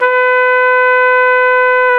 Index of /90_sSampleCDs/Roland LCDP12 Solo Brass/BRS_Piccolo Tpt/BRS_Picc.Tp 1